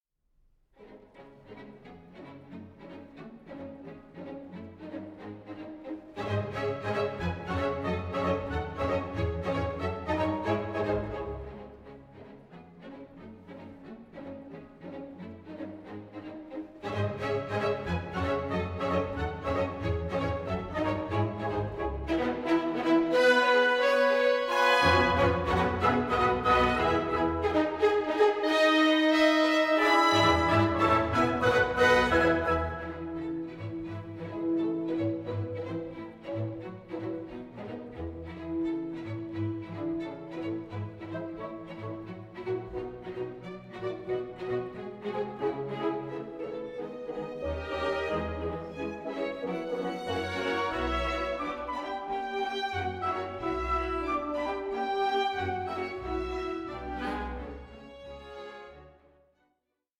sparkling dialogue between strings and winds